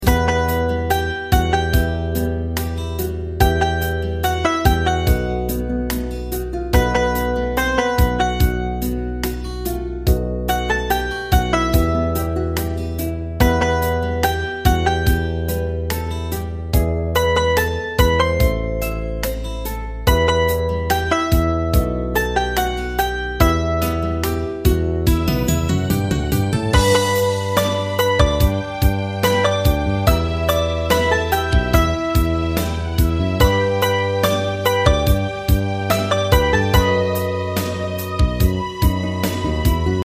大正琴の「楽譜、練習用の音」データのセットをダウンロードで『すぐに』お届け！様々なジャンルの楽曲を取り揃えております。